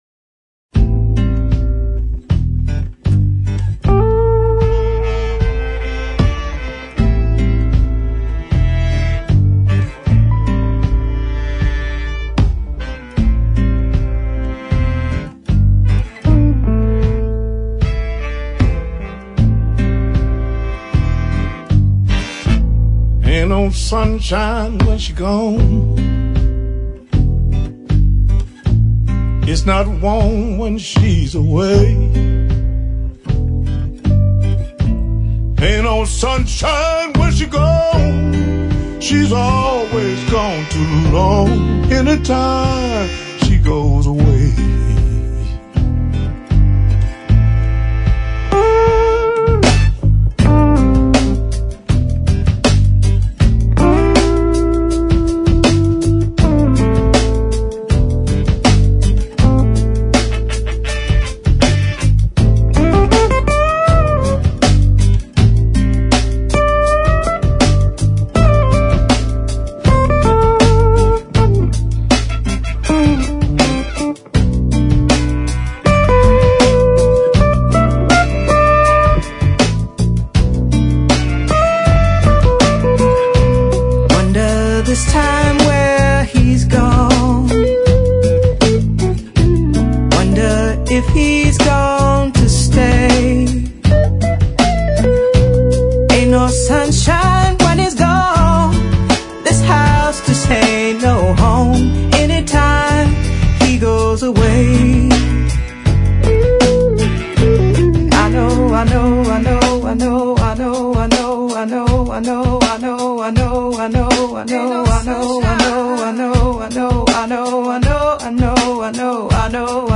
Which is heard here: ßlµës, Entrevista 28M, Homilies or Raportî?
ßlµës